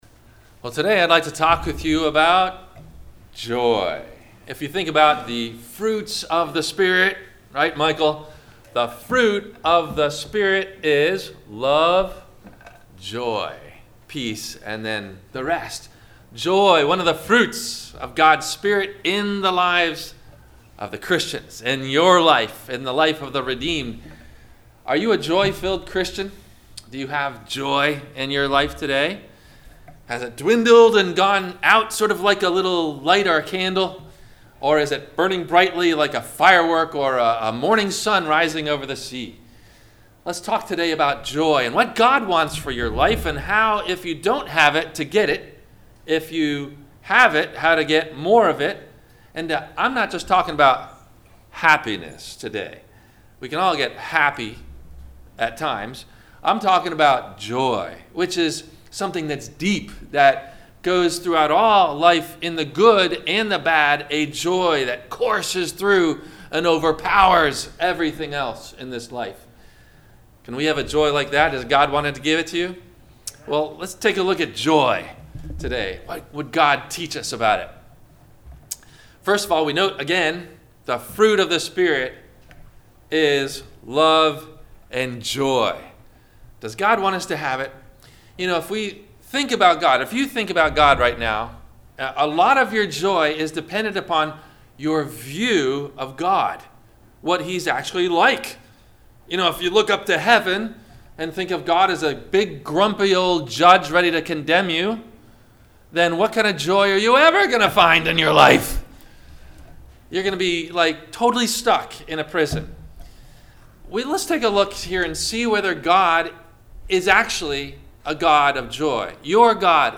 - Sermon - October 13 2019 - Christ Lutheran Cape Canaveral